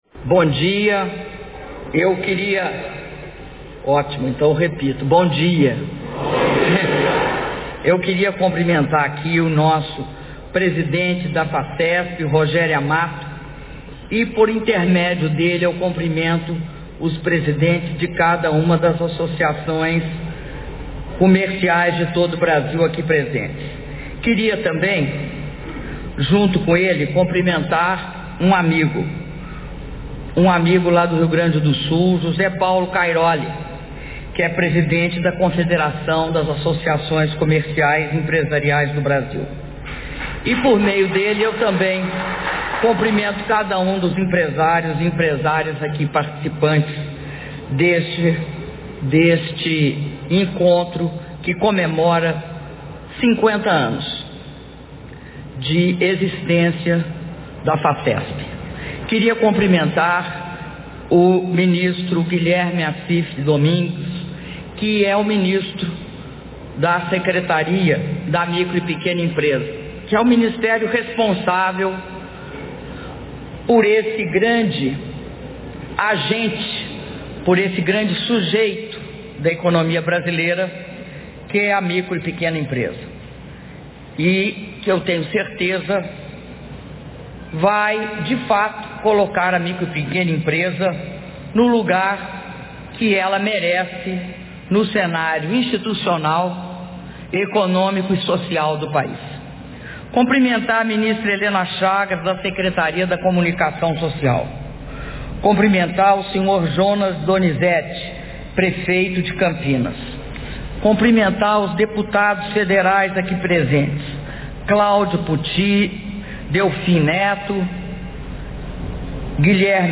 Áudio do discurso da Presidenta da República, Dilma Rousseff, na solenidade de abertura do XIV Congresso da Federação das Associações Comerciais do Estado de São Paulo (Facesp)